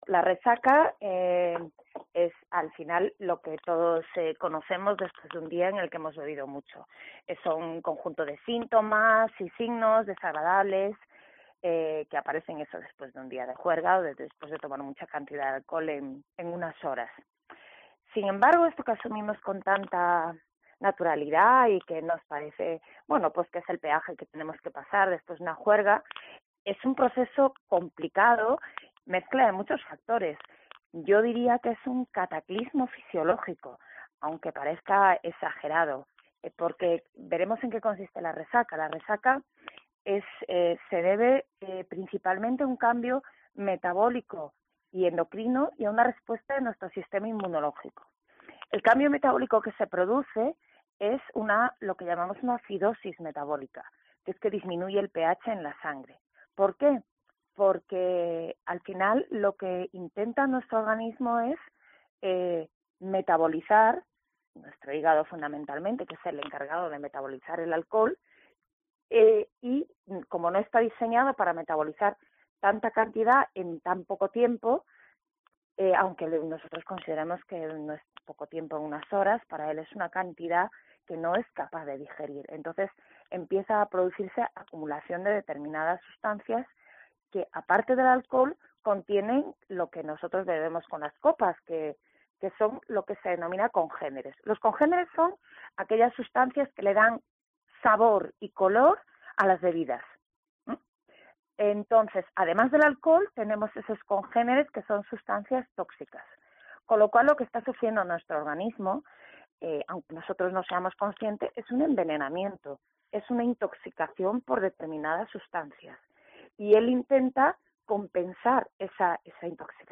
Una doctora experta en adicciones nos aclara lo que se esconde detrás de este tópico